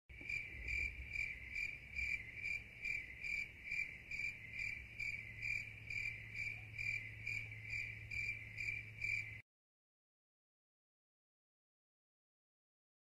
crickets